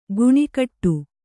♪ guṇikṭṭu